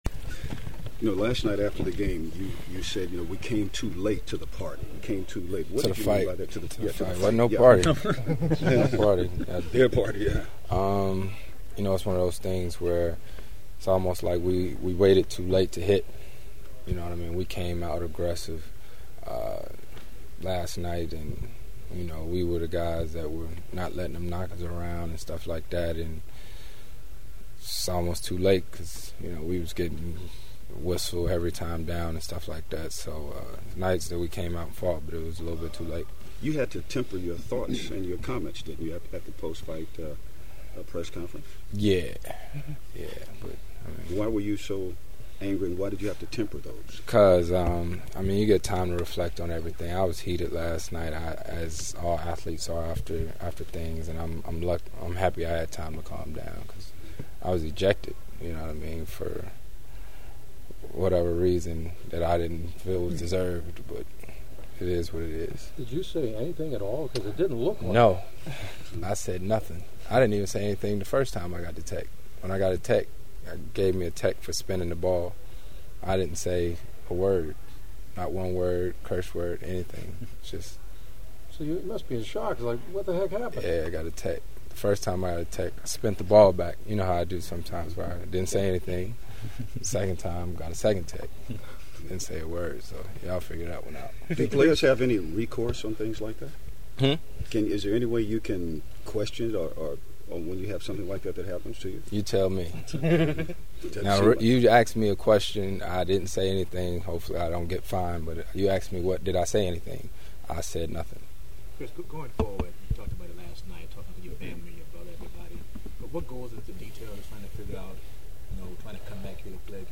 Just hours after being eliminated in Memphis, the Clips were back home doing their exit interviews knowing this would be the last time they’d be together as this collective team before changes will be made.